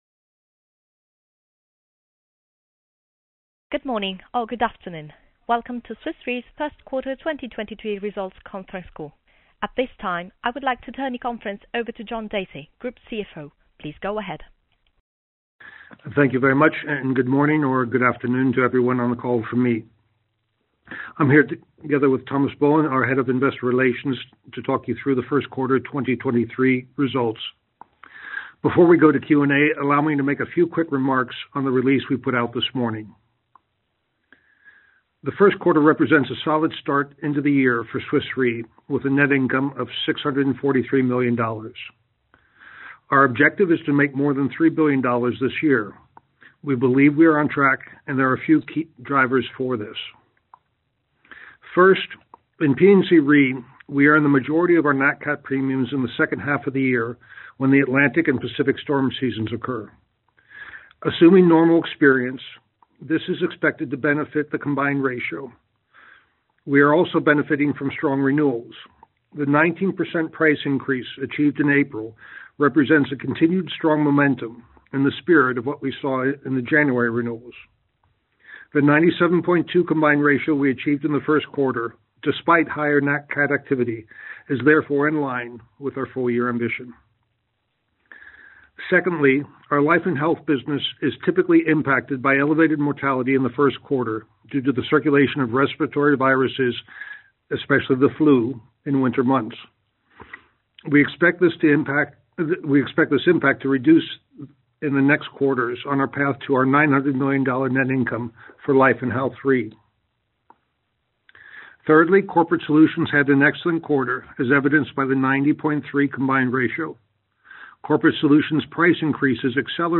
q1-2023-call-recording.mp3